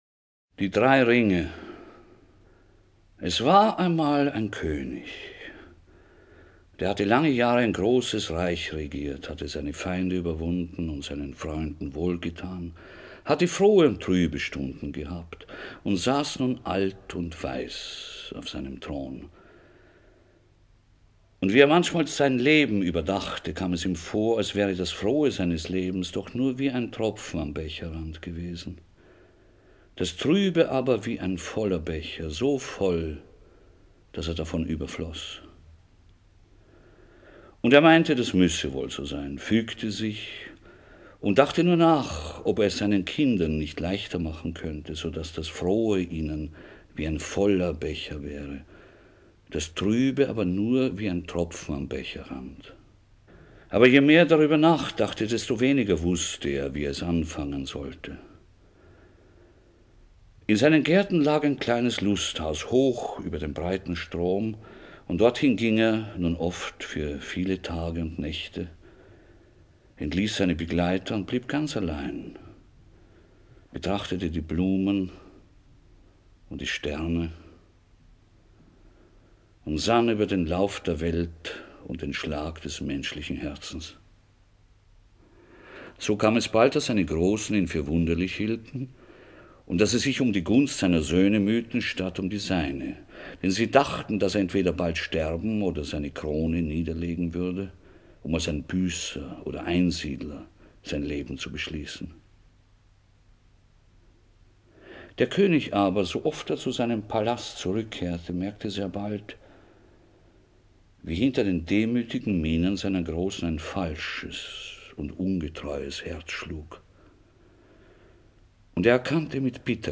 Audio Lesungen
Oskar-Werner-liest-Ernst-Wiechert-Die-drei-Ringe.mp3